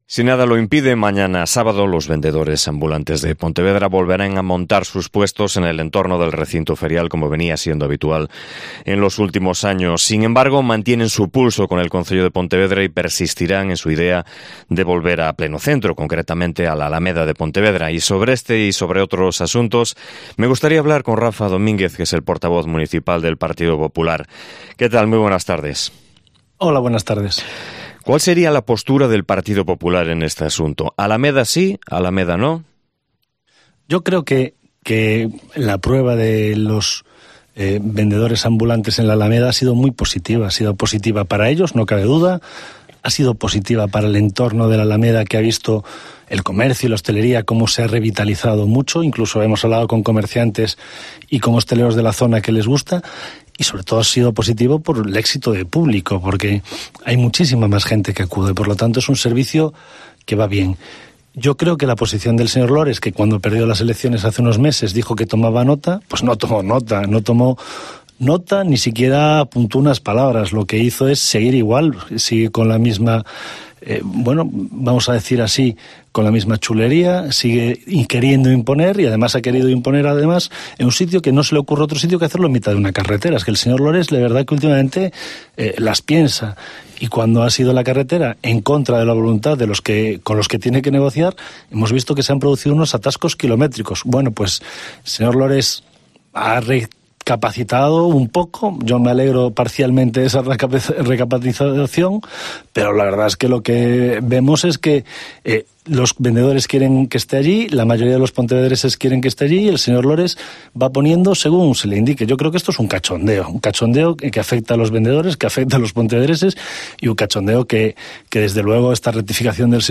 AUDIO: Entrevista patrocinada por el Grupo Municipal del Partido POpular